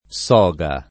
soga [ S0g a ]